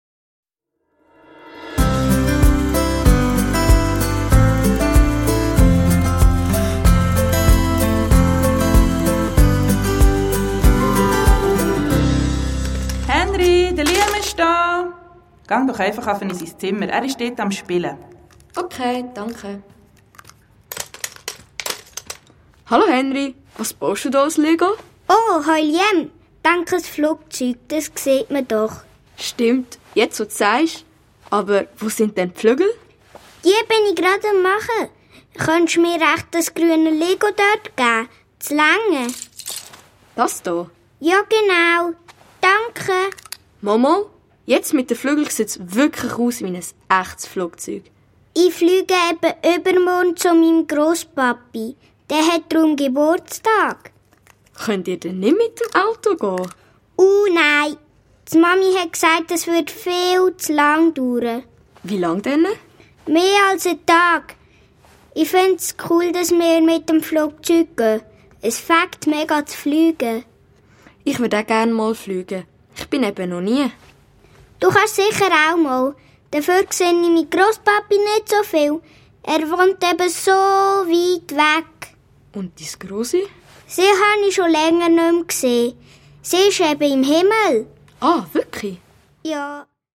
Schweizerdeutsch.
Hörspiel-CD mit Download-Code